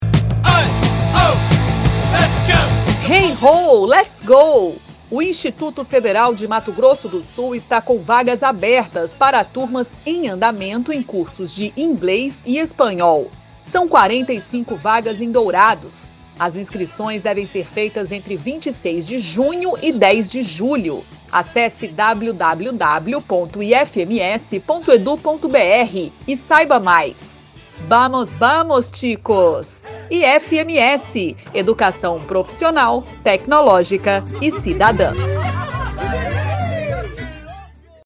Spot - Cursos de Idiomas em Dourados